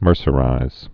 (mûrsə-rīz)